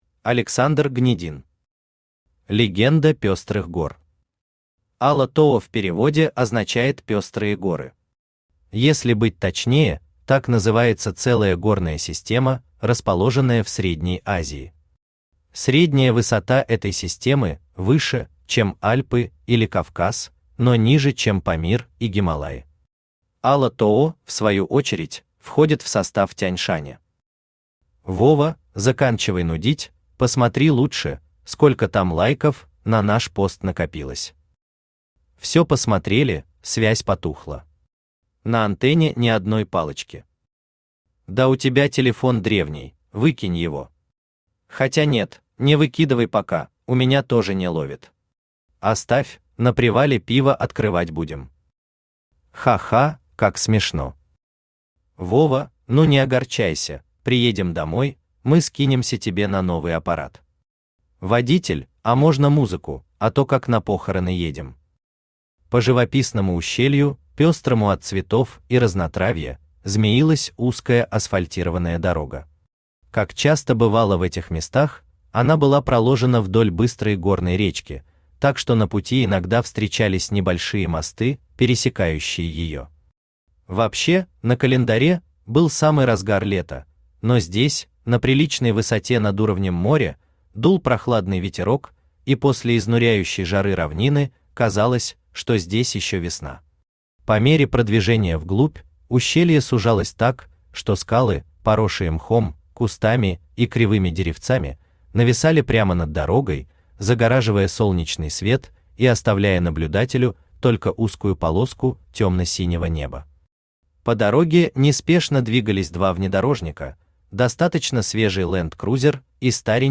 Аудиокнига Легенда пёстрых гор | Библиотека аудиокниг
Aудиокнига Легенда пёстрых гор Автор Александр Гнедин Читает аудиокнигу Авточтец ЛитРес.